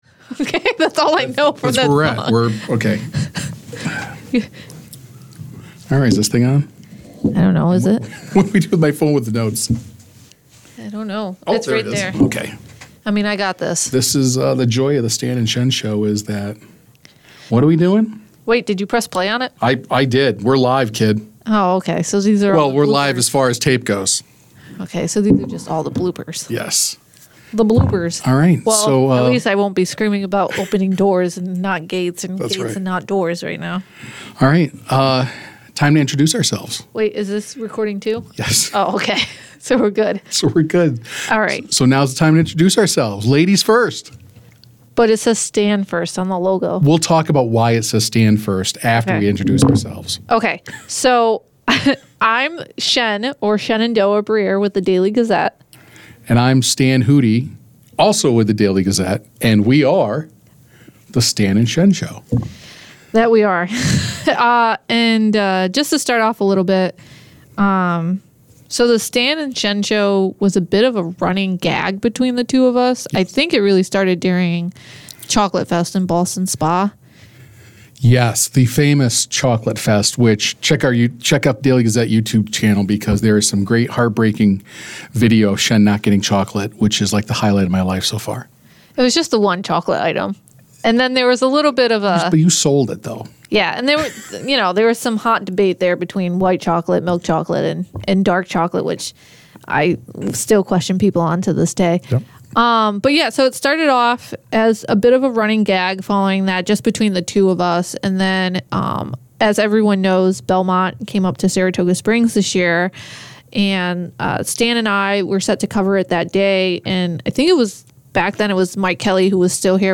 There are plenty of laughs, some gaffs, but that is what you get when these two team up.